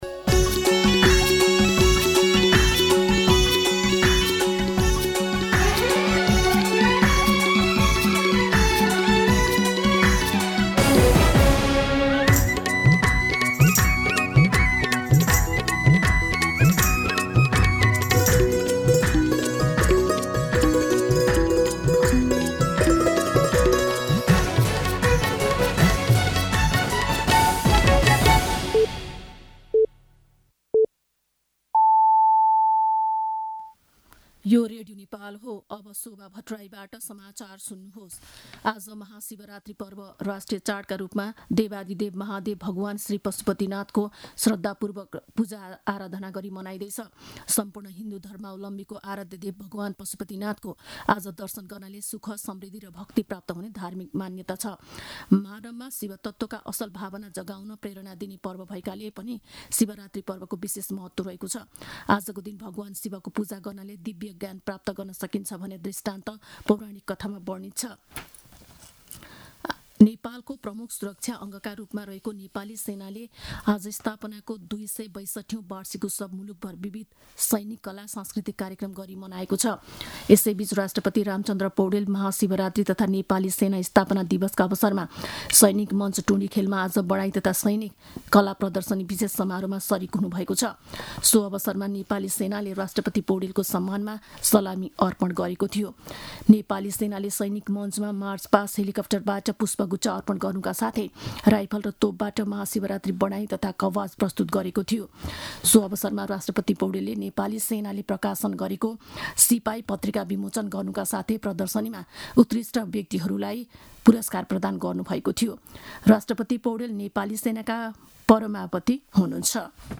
An online outlet of Nepal's national radio broadcaster
दिउँसो ४ बजेको नेपाली समाचार : १५ फागुन , २०८१
4-pm-News-14.mp3